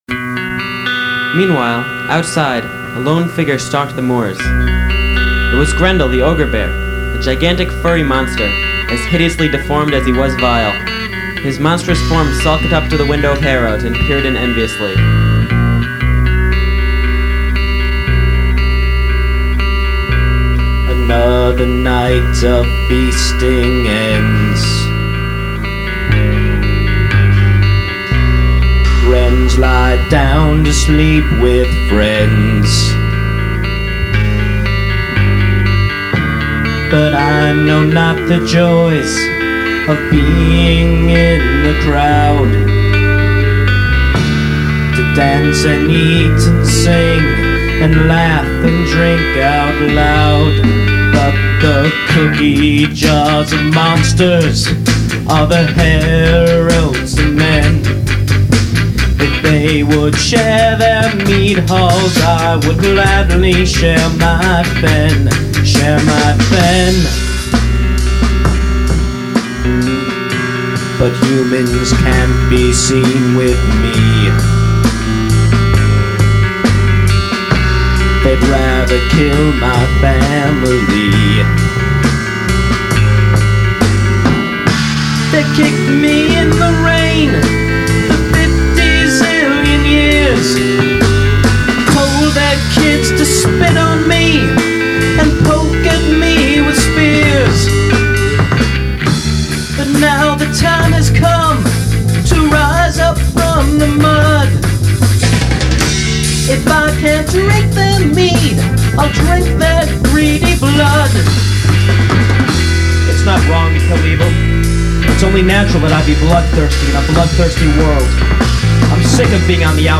vocals
drums, bass, lead guitar